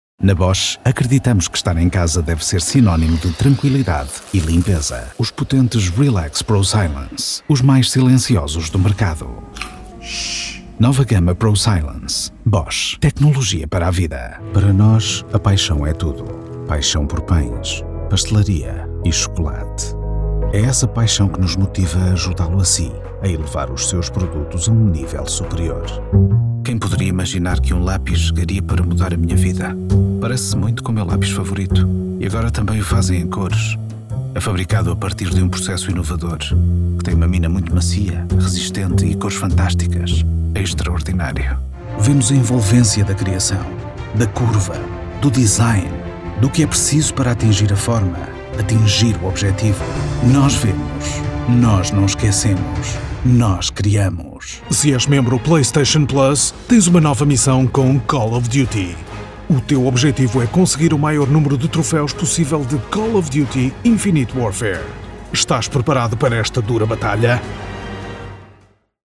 Native speaker Male 30-50 lat
Possesses a mature, baritone voice.
Nagranie lektorskie